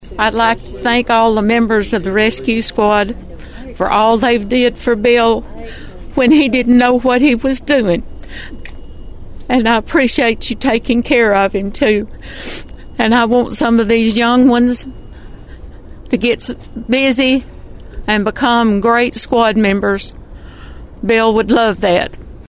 A ceremony was held Monday night to honor a former longtime member of the Obion County Rescue Squad.